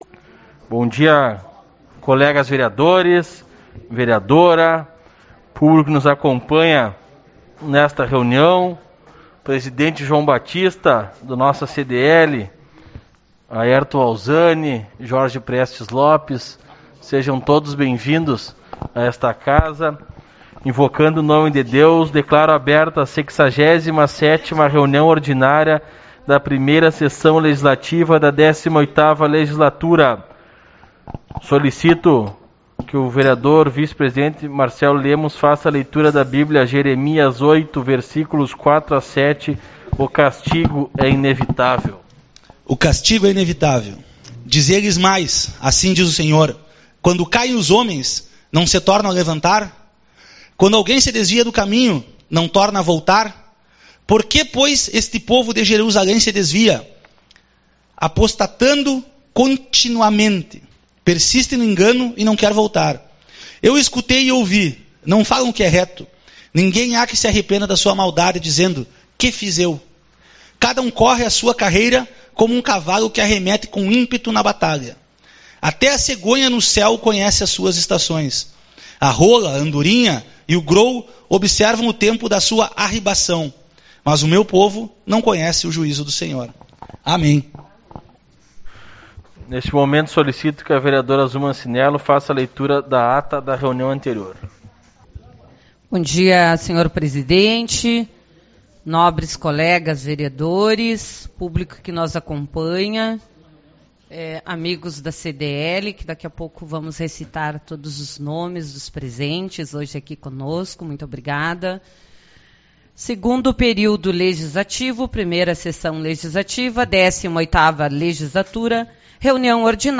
14/10 - Reunião Ordinária